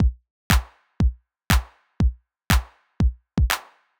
ITA Beat - Kick _ Clap.wav